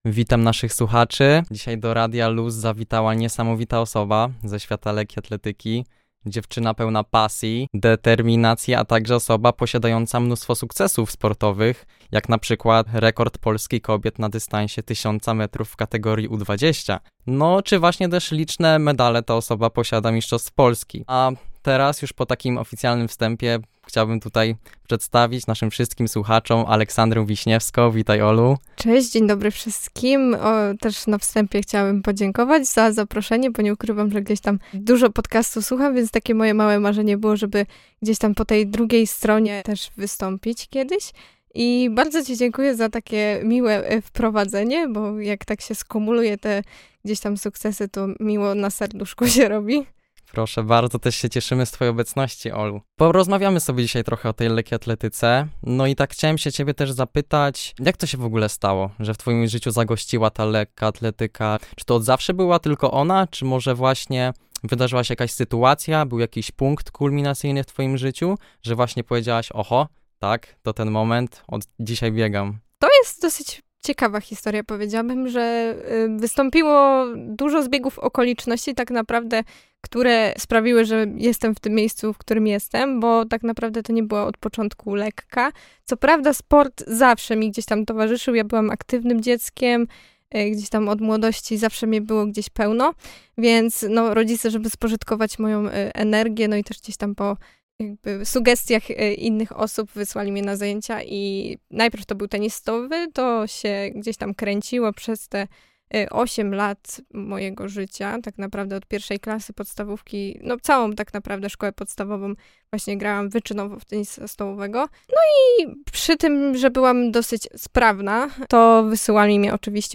wywiad-lekka-atletyka.mp3